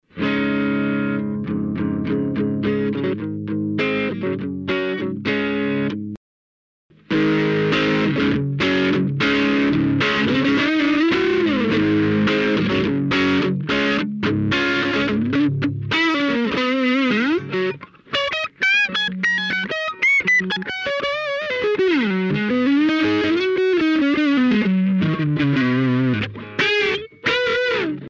...Um dois overdrives mais famosos do mundo similar TS-808, com melhoras de graves,  controles de volume, tone, drive (ganho projetado dar a flexibilidade máxima, no mínimo fica extremamente limpo o som)
Equipamento usado na gravação dos samples:
Guitarras: Gibson Les Paul Standard 1993 com captadores Gibson PAF Classic 57, Fender Stratocaster American Standard 1993 com caps Fender Lace Sensor Gold
Amplificador:Fender Bassman 59 Reissue (2003)
Microfone:Shure SM 57 Mesa de Som: Tapco/Mackie 6306 Placa:Creative Labs Live 5.1 Software: Sonar Producer Edition 4.0 Cabos: Spectraflex